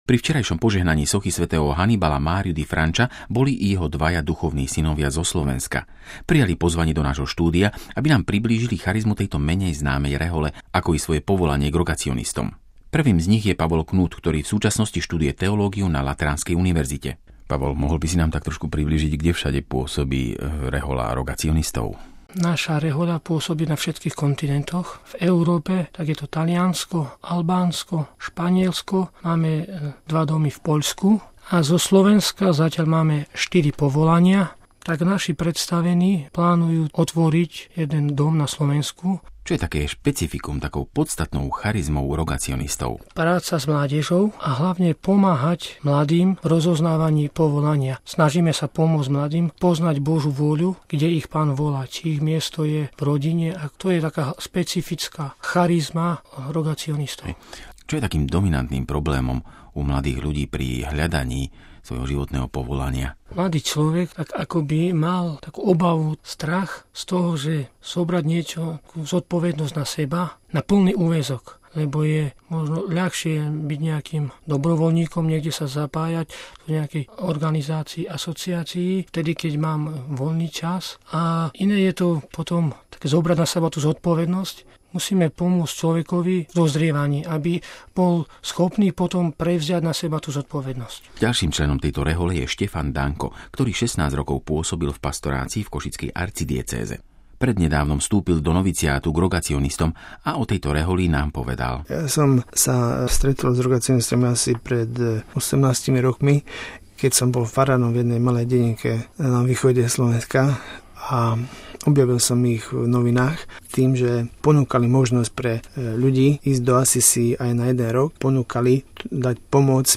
Rozhovor s členmi rehole rogacionistov zo Slovenska